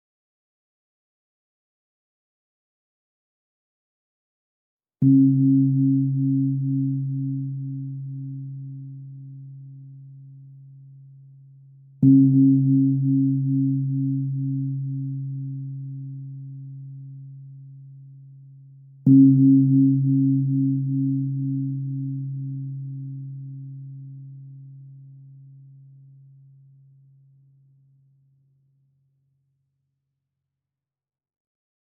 Meinl Sonic Energy 18" Thai Gong (THG18)